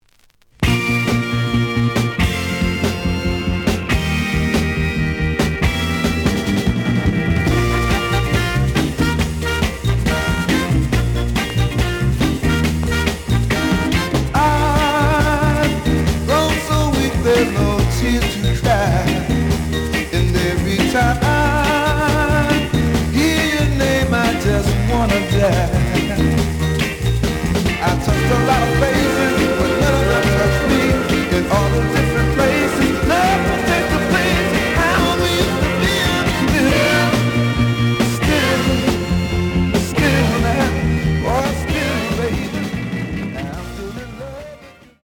The audio sample is recorded from the actual item.
●Genre: Soul, 60's Soul
B side is slight cracking sound. A side plays good.)